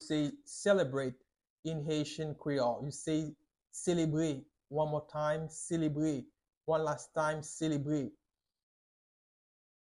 Listen to and watch “Selebre” audio pronunciation in Haitian Creole by a native Haitian  in the video below: